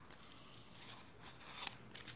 标签： 刷涂
声道立体声